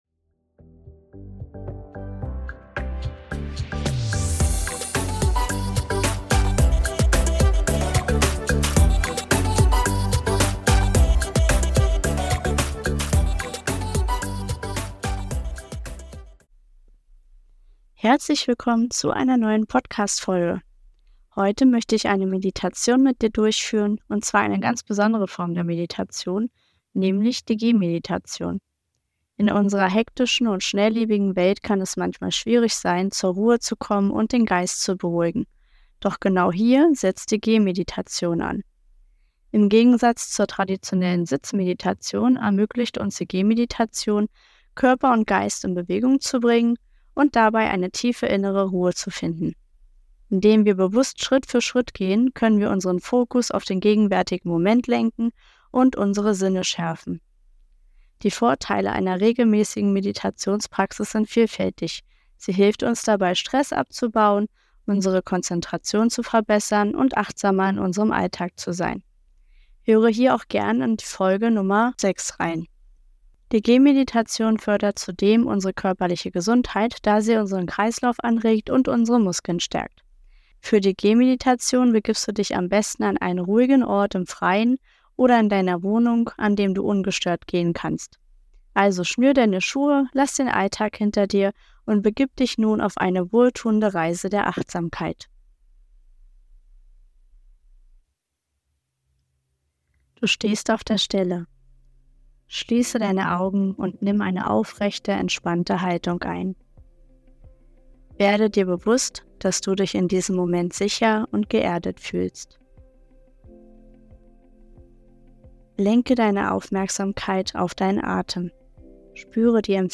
In dieser Folge leite ich dich durch eine kurze Gehmeditation.